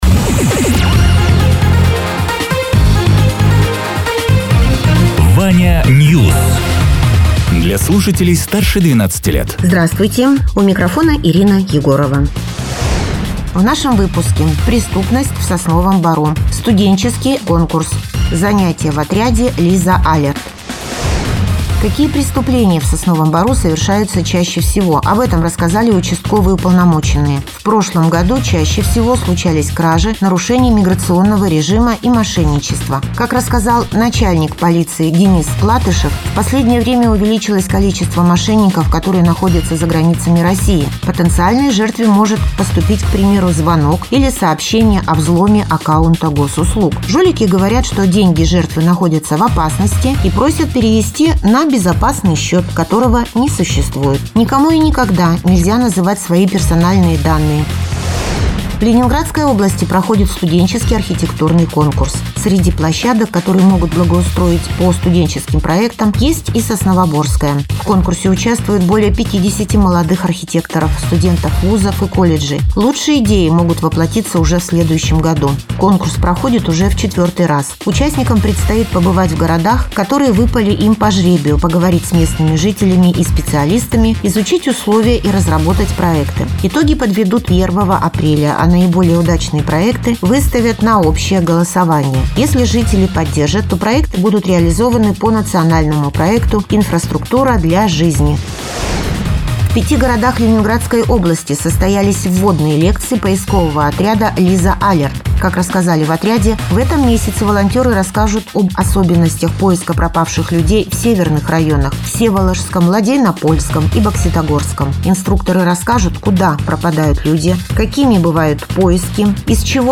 Радио ТЕРА 12.03.2026_10.00_Новости_Соснового_Бора